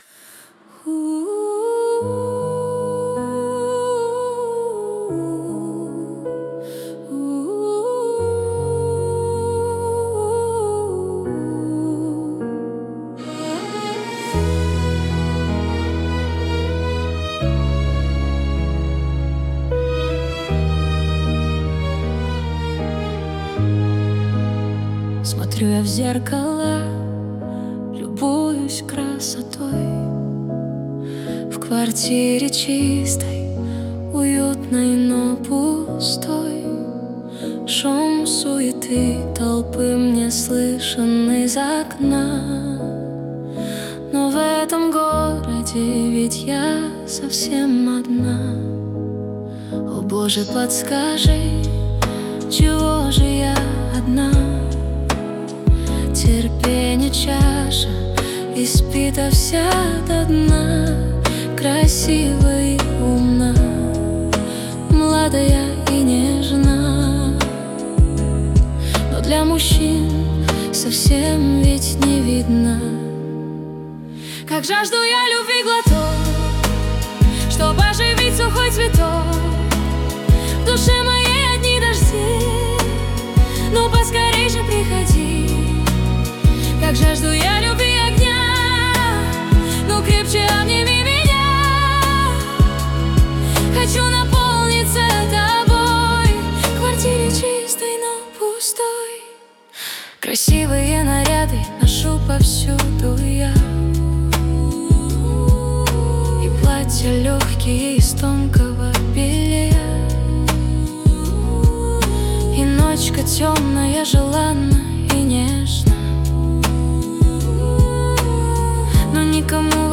mp3,5111k] AI Generated